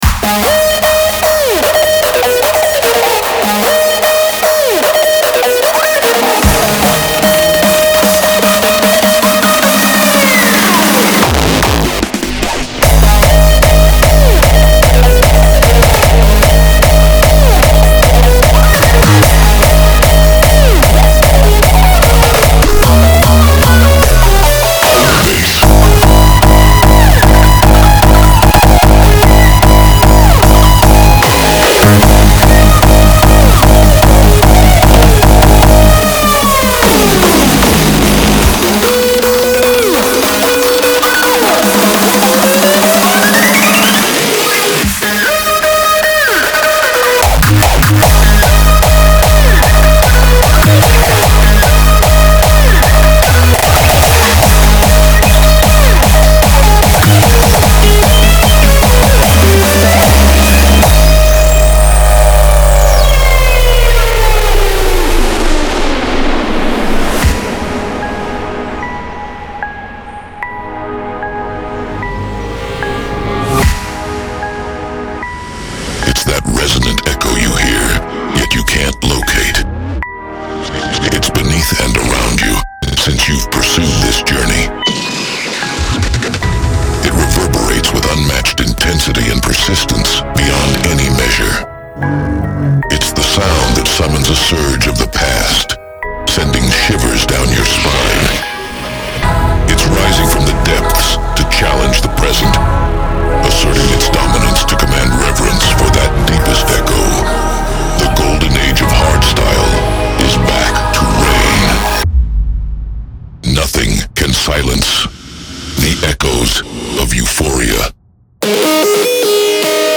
• Жанр: Hardstyle